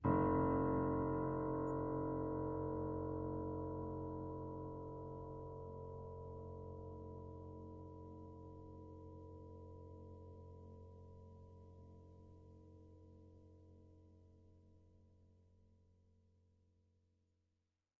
描述：记录了一架GerardAdam钢琴，它至少有50年没有被调音了！
Tag: 失谐 恐怖 踏板 钢琴 弦乐 维持